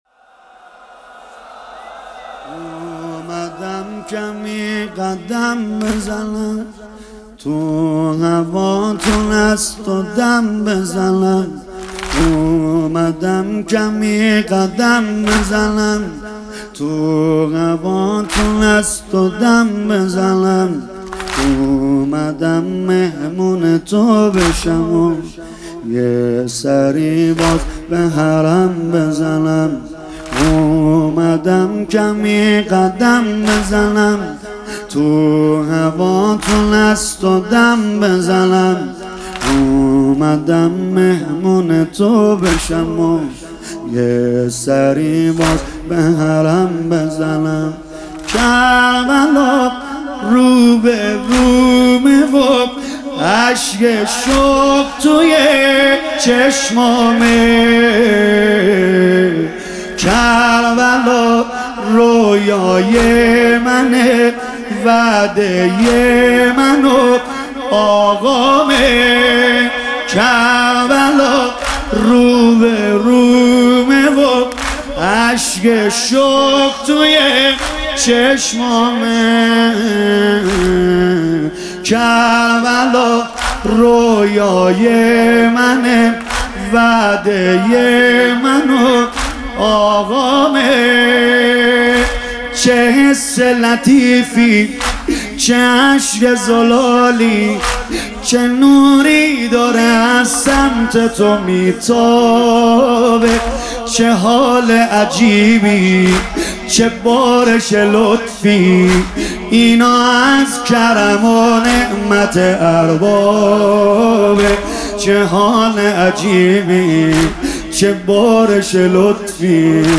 شب چهارم محرم - دوطفلان حضرت زینب سلام الله علیها
سیدرضانریمانی